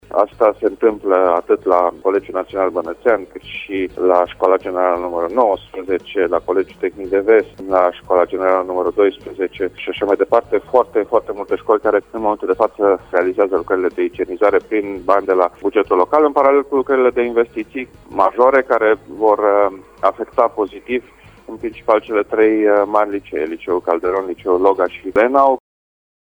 Banii vor fi utilizaţi pentru dotări şi lucări de modernizare, a declarat pentru Radio Timişoara, viceprimarul Timişoarei, Dan Diaconu.
Viceprimarul Dan Diaconu a mai precizat că în prezent se execută lucrări de igienizare şi de refacere a grupurilor sanitare în aproape toate şcolile.